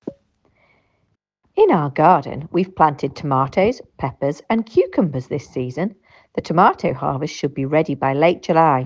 2. GB accent: Tomato